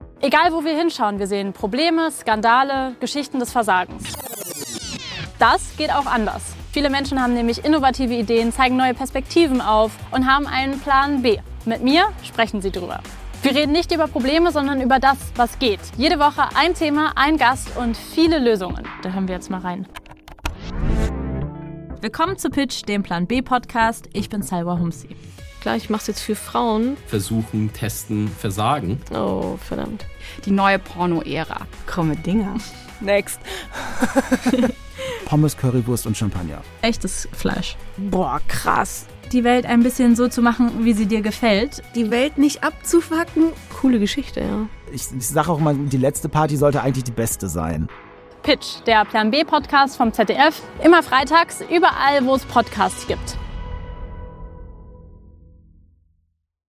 Lösungen statt Probleme: Darum geht es bei “Pitch”, dem konstruktiven “Plan b”-Podcast. Jede Woche ein Gast, jede Woche ein Thema.